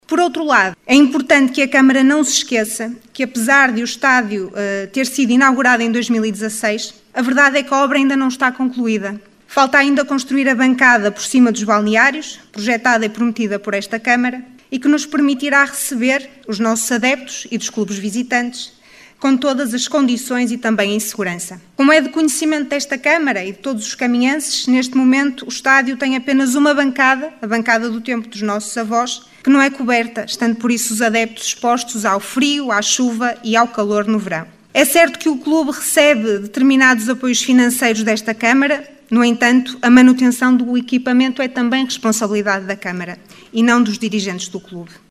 O assunto foi levado à Assembleia no período destinado às intervenções do público pela voz de uma delegada da assembleia de freguesia de Lanhelas, Joana Whyte, eleita pela coligação OCP.